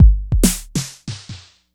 TSNRG2 Breakbeat 007.wav